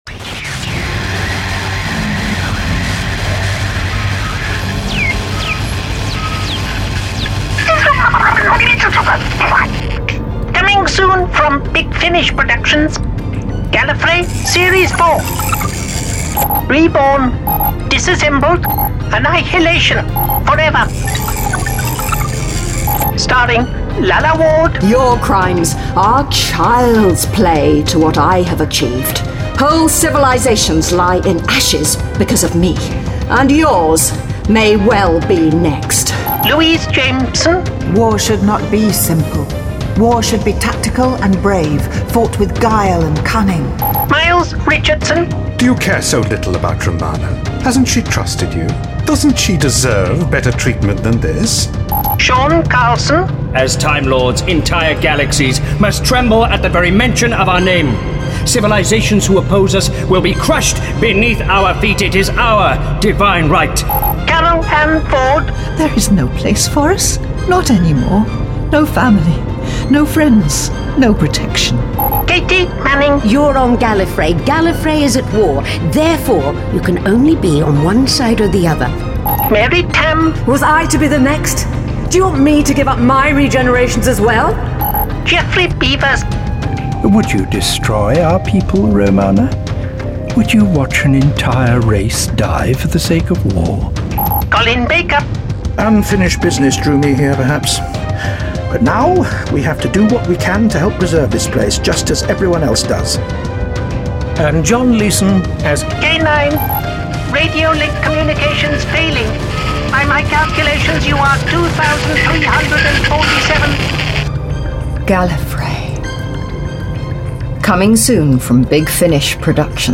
Award-winning, full-cast original audio dramas from the worlds of Doctor Who, Torchwood, Blake's 7, Class, Dark Shadows, The Avengers, Survivors, The Omega Factor, Star Cops, Sherlock Holmes, Dorian Gray, Pathfinder Legends, The Prisoner, Adam Adamant Lives, Space 1999, Timeslip, Terrahawks, Space Precinct, Thunderbirds, Stingray, Robin Hood, Dark Season, UFO, Stargate